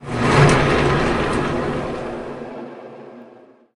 FanOff.ogg